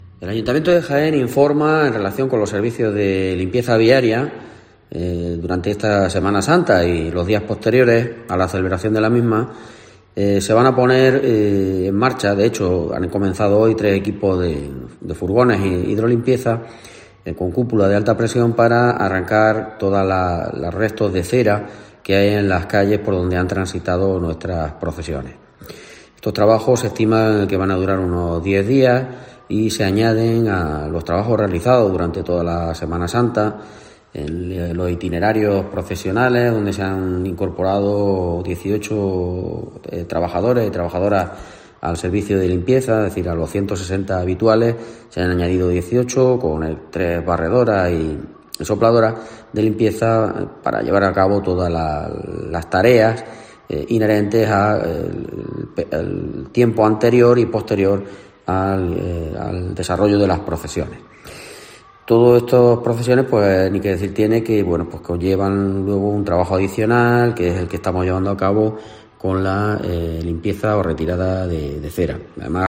El concejal de Control de Servicios Públicos y Contratación, Francisco Lechuga